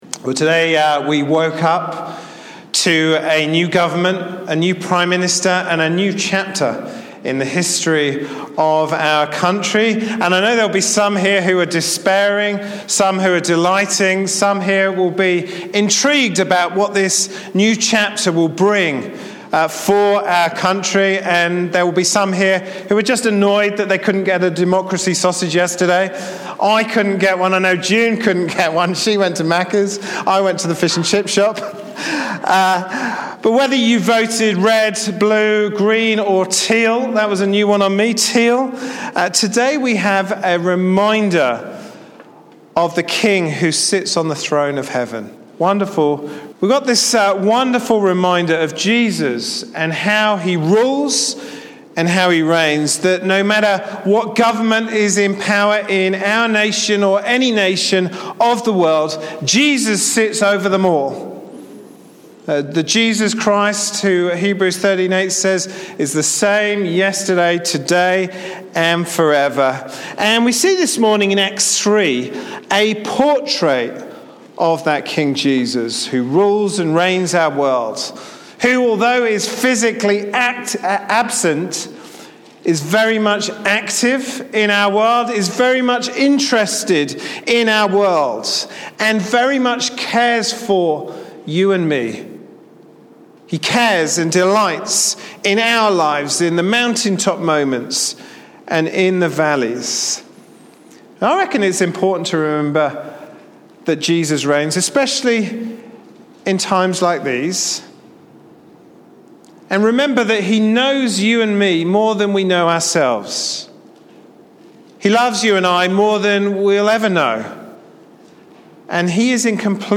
Passage: Acts 3:1-4:4 Service Type: Sunday morning service